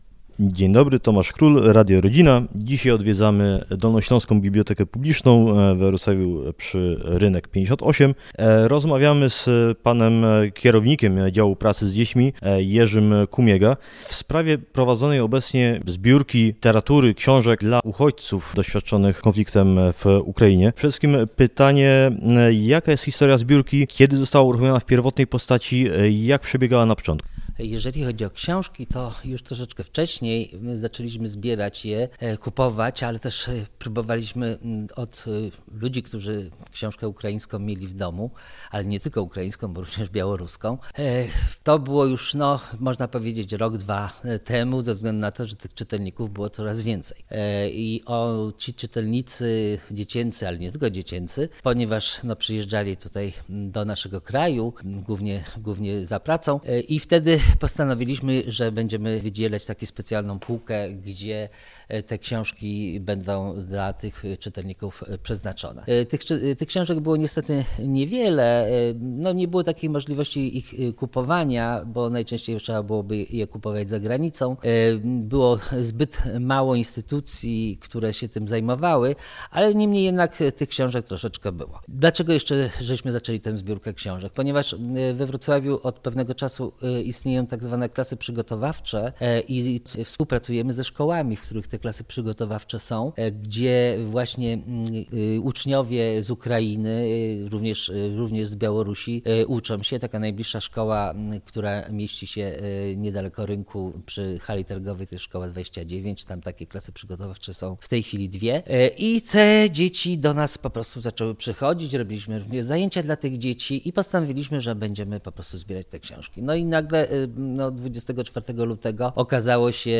Cala-rozmowa.mp3